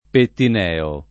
Pettineo [ pettin $ o ]